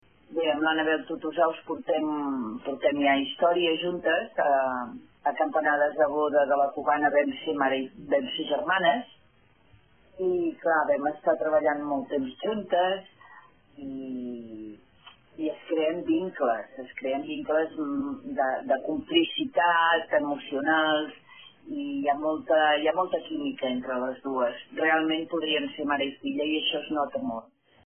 Declaracions de Mont Plans: